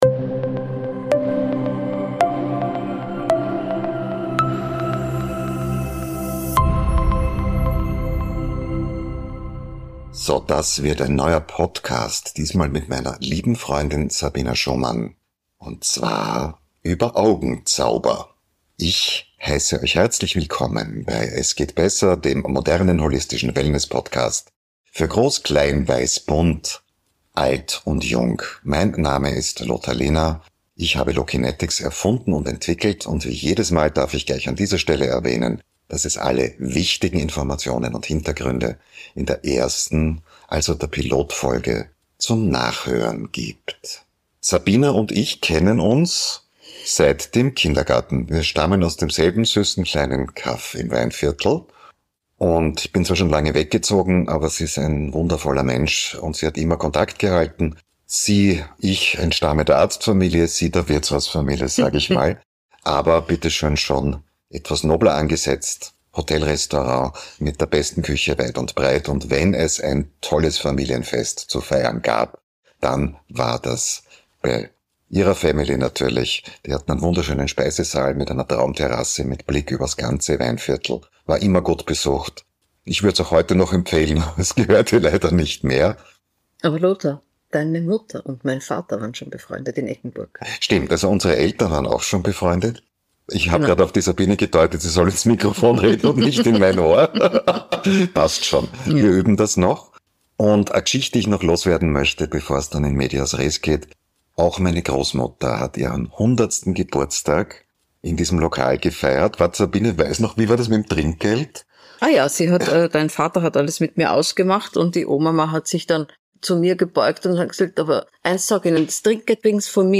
Ein inspirierendes Gespräch über Körper, Emotionen und die Kraft kleiner Routinen für deine Augen.